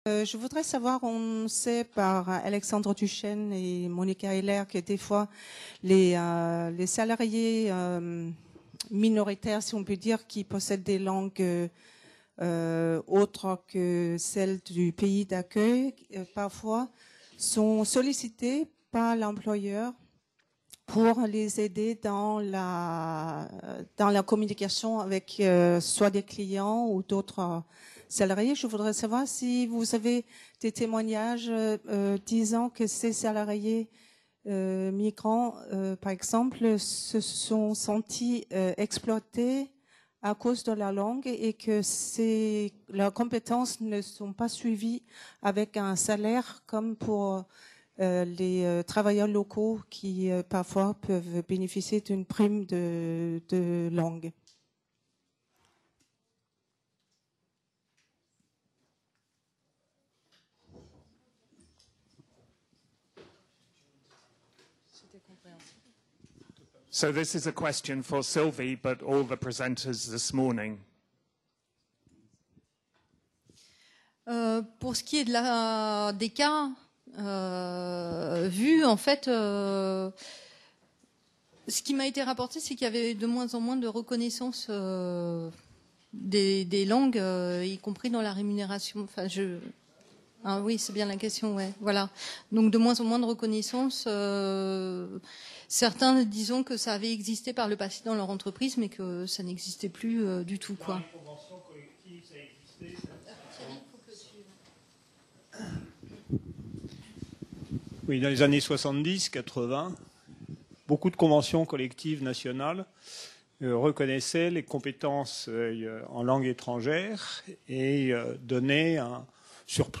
Discussion - Présentation des résultats du projet Européen IR-MultiLing | Canal U